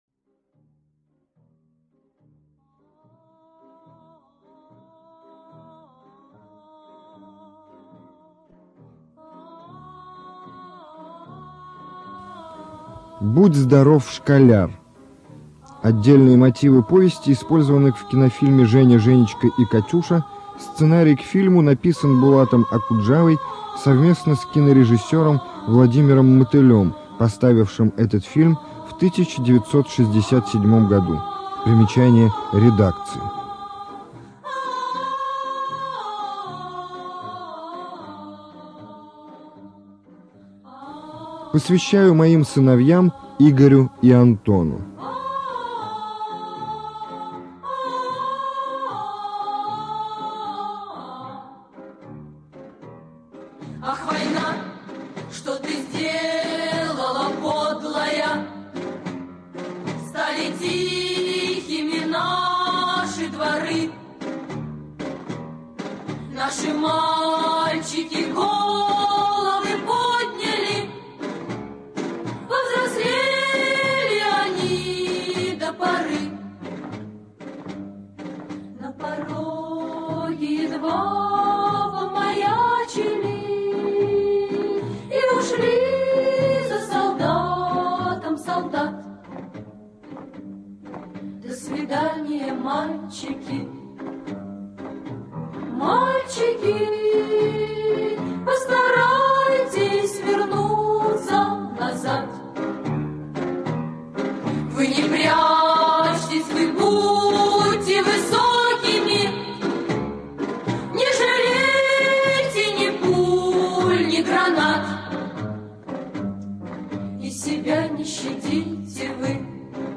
ЖанрДетская литература, Советская проза
Студия звукозаписиСанкт-Петербургская государственная библиотека для слепых и слабовидящих